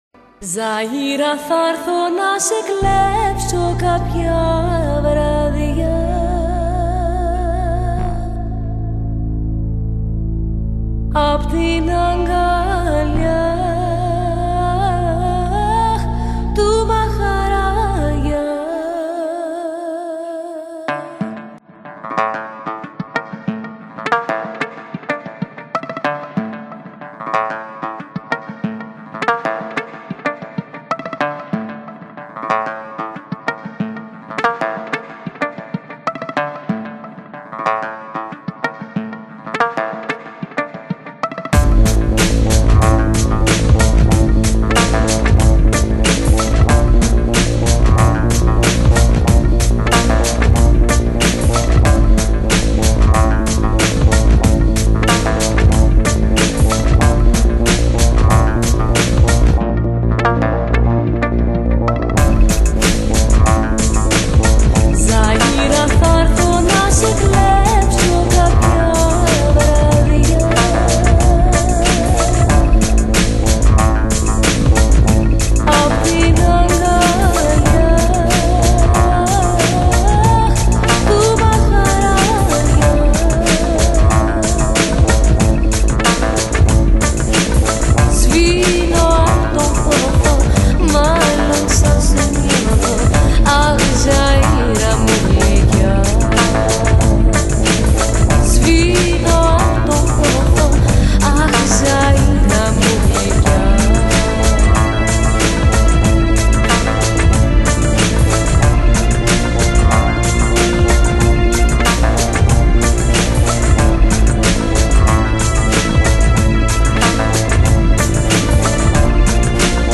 Dance
CD1中都是颇为舒缓的地方特色选曲